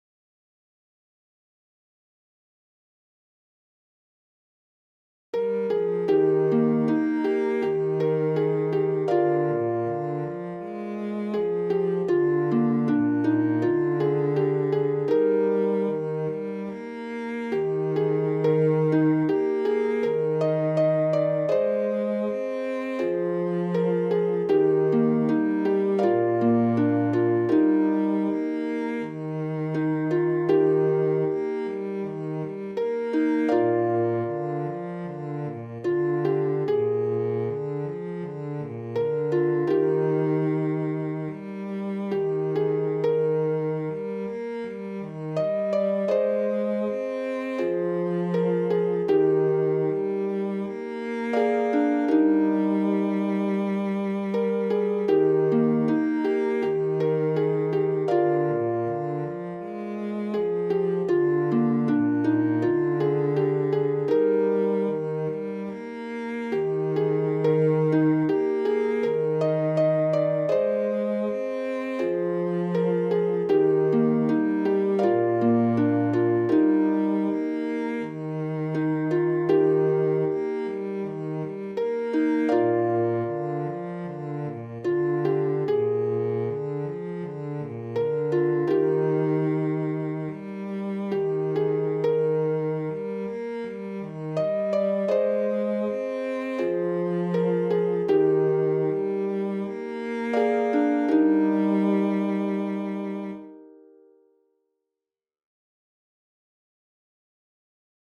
Syksyihminen-harput-ja-sello.mp3